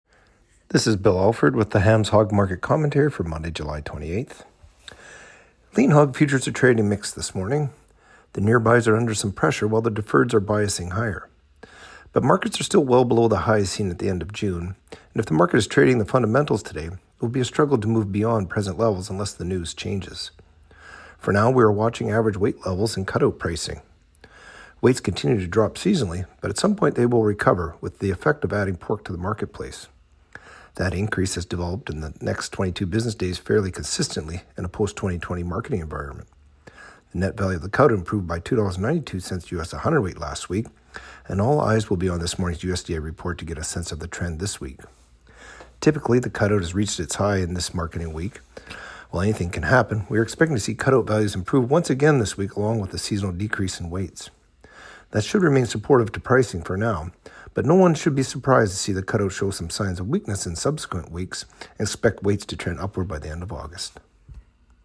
Hog-Market-Commentary-Jul.-28-25.mp3